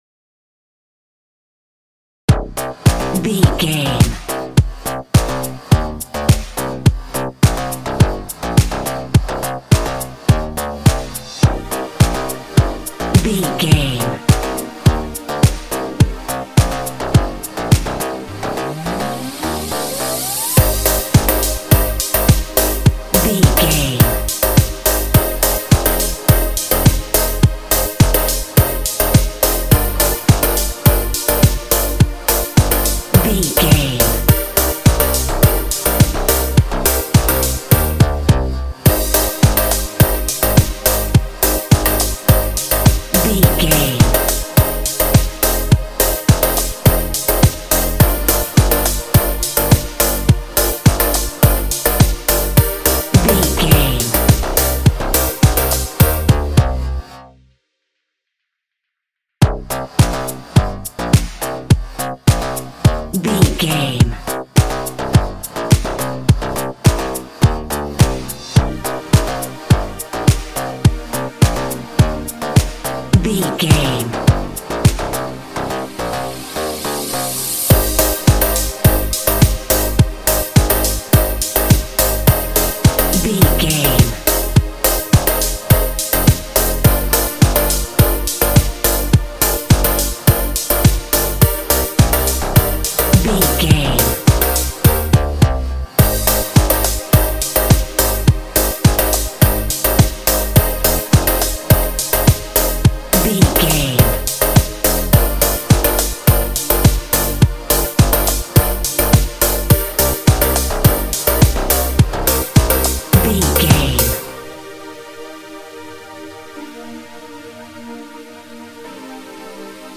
Clubbers Music for Cool Dancers.
Aeolian/Minor
high tech
uplifting
futuristic
hypnotic
dreamy
smooth
drum machine
synthesiser
house
electro dance
techno
trance
synth leads
synth bass
upbeat